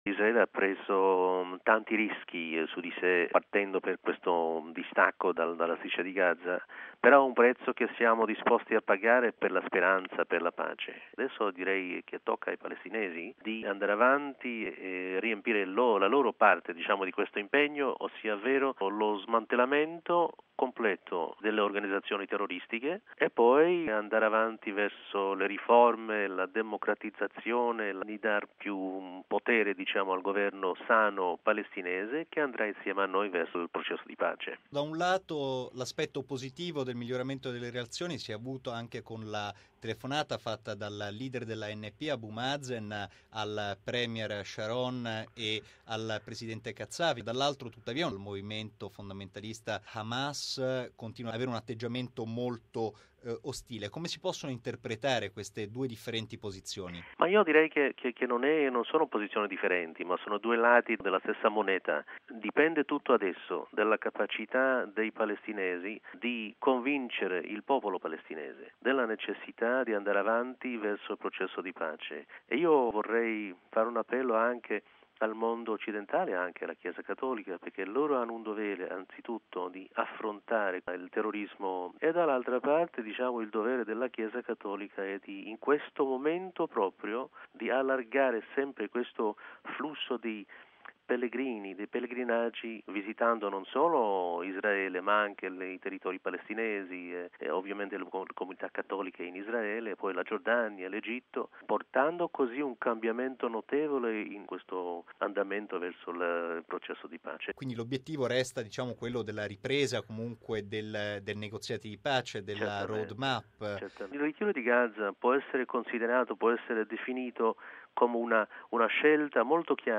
Ma col ritiro israeliano in fase di completamento, quali sviluppi si prospettano per la questione israelo-palestinese? Risponde l’ambasciatore di Israele presso la Santa Sede, Ben Hur: RealAudio